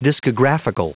Prononciation du mot discographical en anglais (fichier audio)
Prononciation du mot : discographical